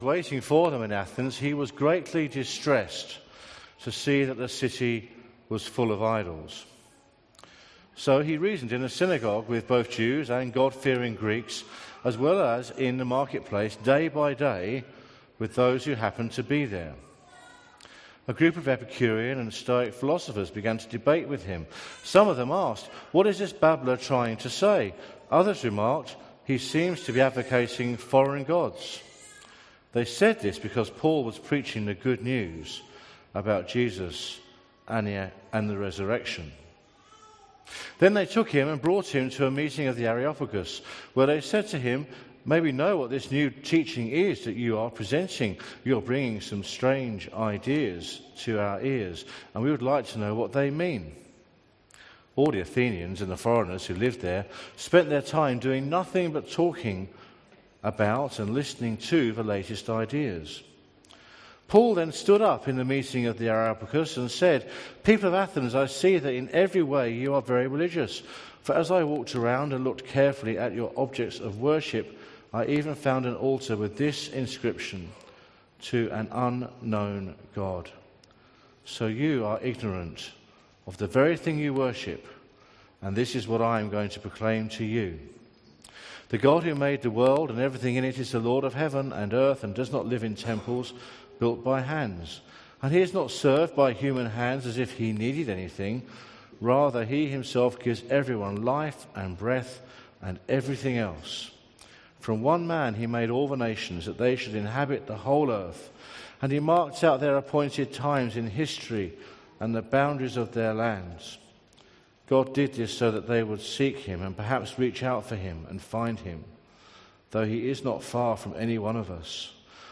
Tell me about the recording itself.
Bible reading and sermon from the 10AM meeting on 21/05/2017 at Newcastle Worship & Community Centre of The Salvation Army. The Bible reading was taken from Acts 17:22�31 & John 14:15�17. By accident this podcast is truncated at the beginning.